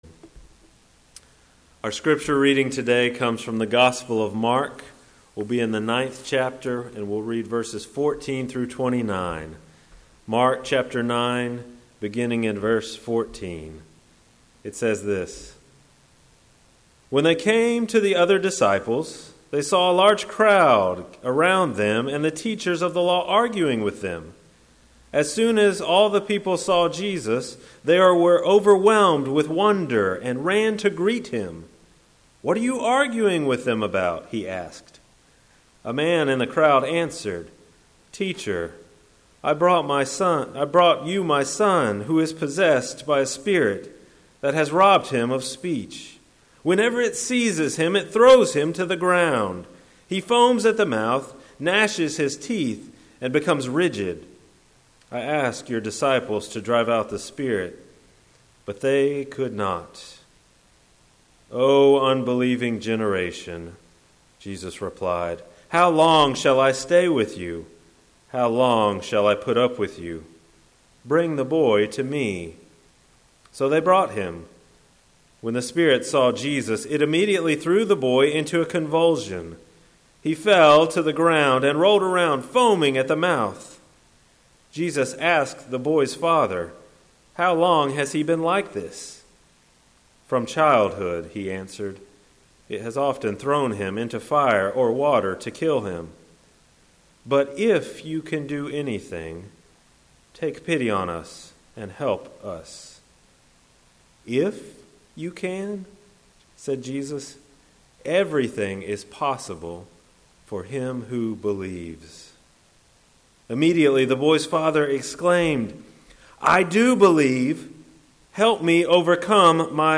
Chatsworth First Methodist Church Sermons